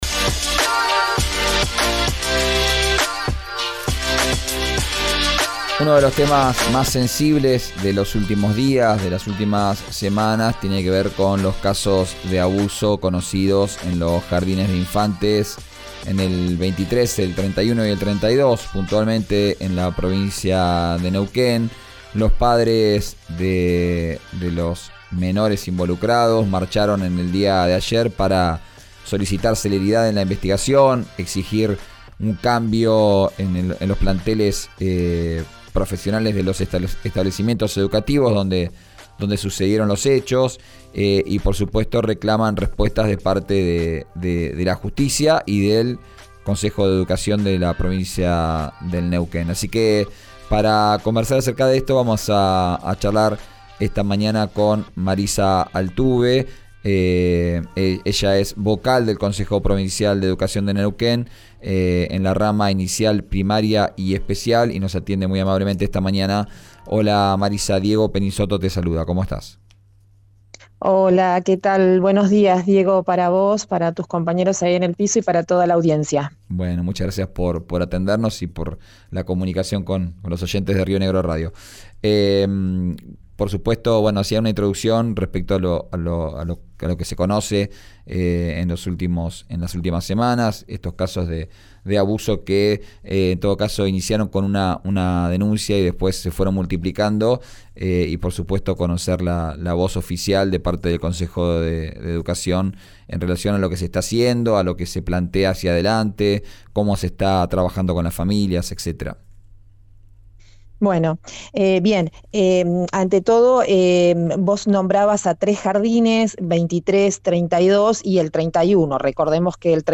En diálogo con “Vos al aire” en RÍO NEGRO RADIO marcó que la situación en cada establecimiento es distinta.